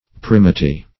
Primity \Prim"i*ty\, n. Quality of being first; primitiveness.